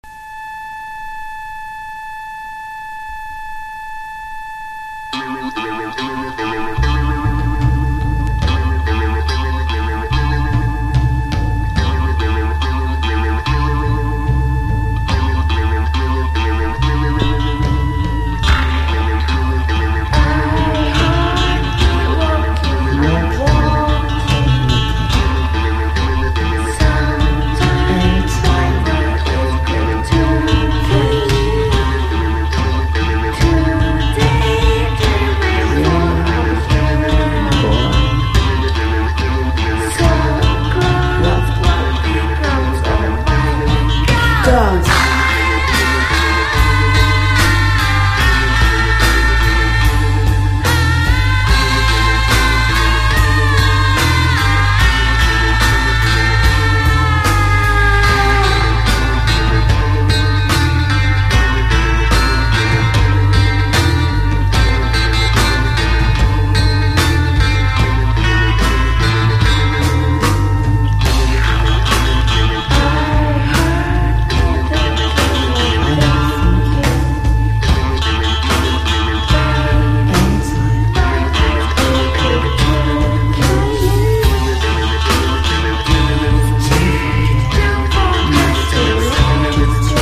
何やら得体の知れないグルーヴとクラウト且つミニマルなニューウェイヴ的サウンドは元祖トランス。